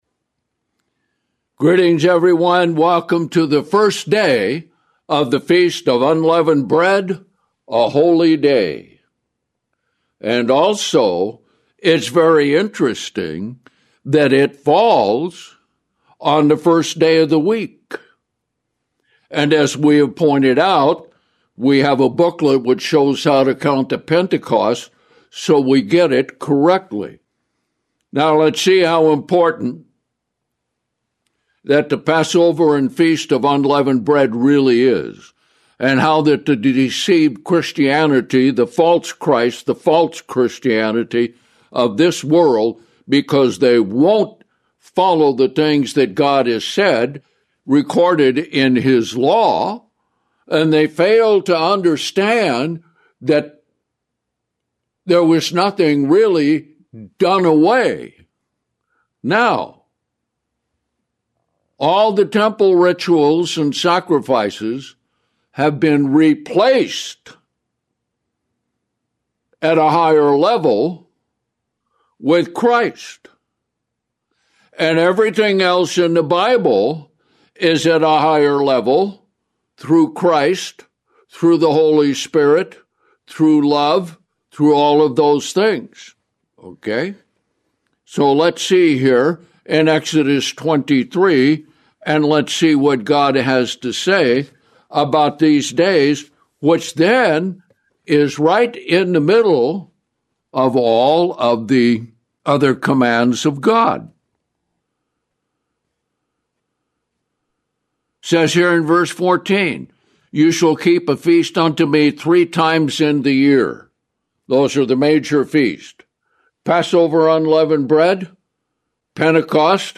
This sermon unpacks the symbolism: leaven representing sin, malice, wickedness, and the carnal ways of the world, while unleavenedness represents the purity, righteousness, and truth found only in Christ. Explore key scriptures (Romans 6, 12; Ephesians 4, 5; Colossians 3; Hebrews 12) that detail the ongoing process of conversion: putting off the "old man" corrupted by deceitful lusts and putting on the "new man" created in God's righteousness.